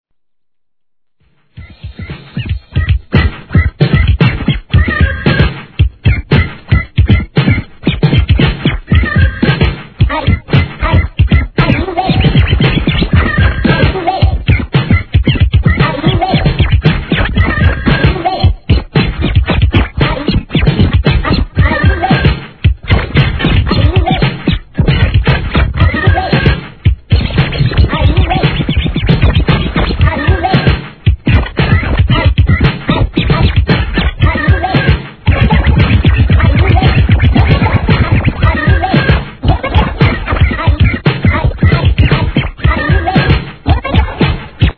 HIP HOP/R&B
荒削りなスクラッチに大胆なブレイク、OLD SCHOOLを感じずにはいられない傑作インストブレイク！！